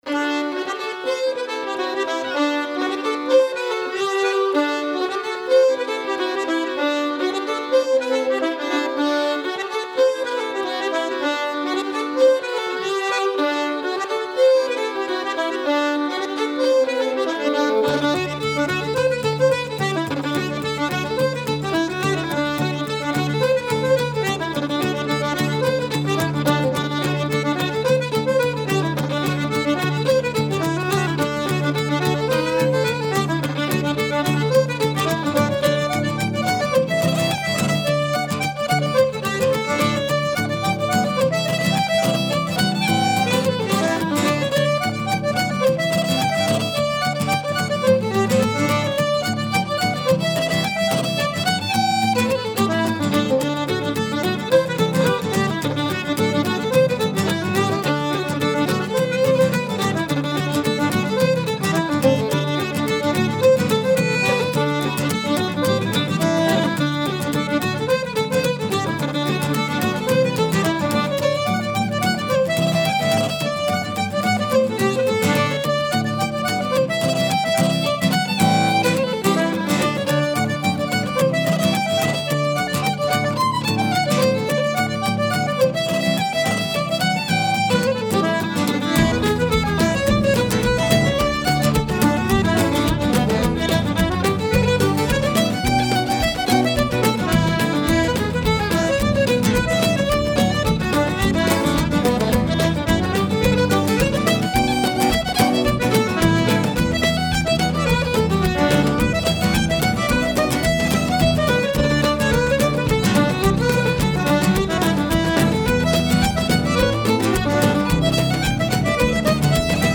This Columbus Irish Band variety of instruments: fiddle, guitar, mandolin, flute, button accordion, whistle, bones, bodhran and bass combined with happy and haunting vocals pay homage to the true spirit of Irish music. The tunes are driven with deliberate purpose.
Kansas-City-Irish-Band-1-AtlantaReelMP3.mp3